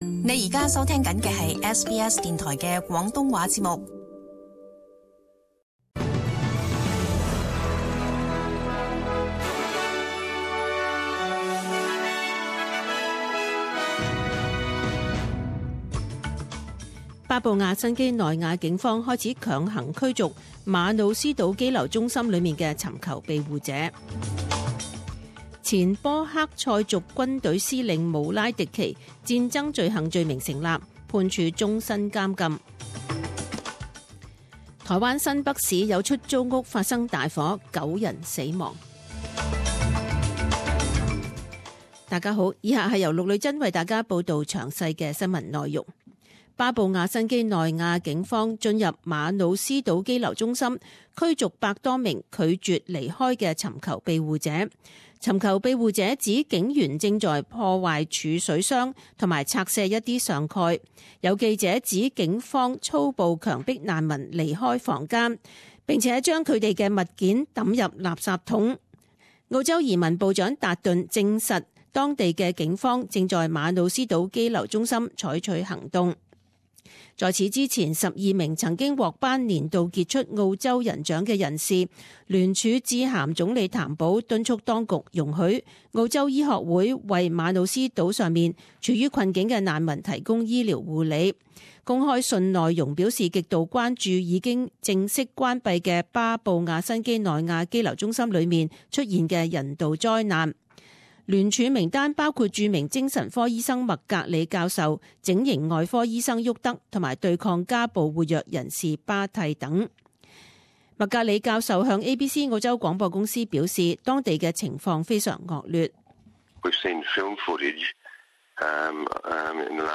十点钟新闻报导 （十一月二十三日）